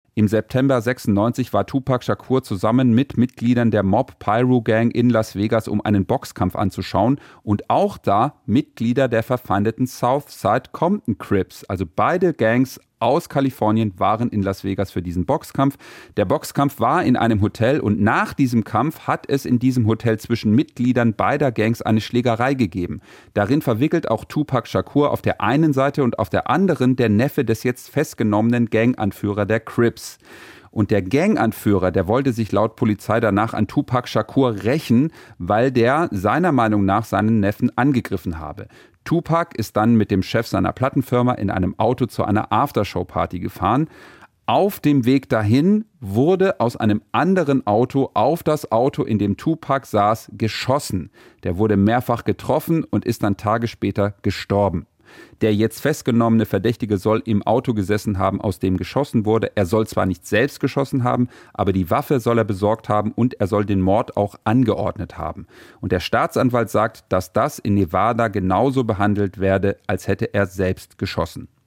Nachrichten So soll es zum Mord an Tupac Shakur gekommen sein